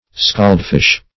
Search Result for " scaldfish" : The Collaborative International Dictionary of English v.0.48: Scaldfish \Scald"fish`\, n. [Scald, a. + fish.]
scaldfish.mp3